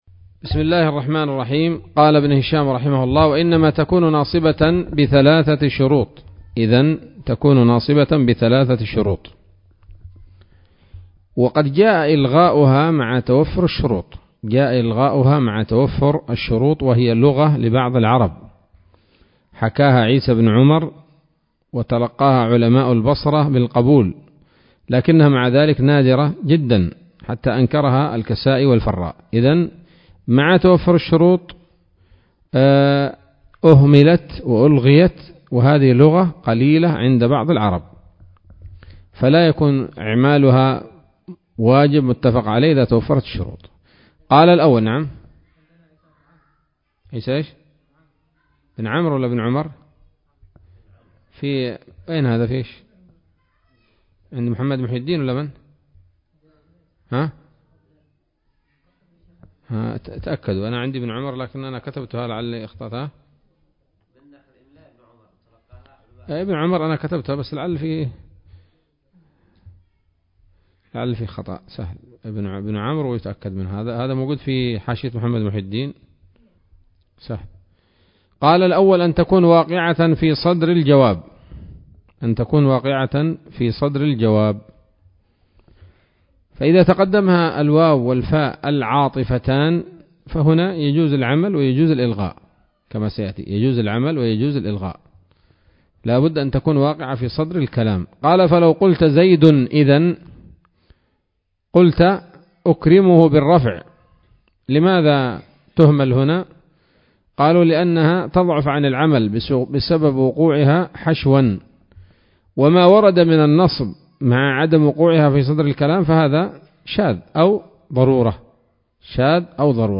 الدرس الثامن والعشرون من شرح قطر الندى وبل الصدى [1444هـ]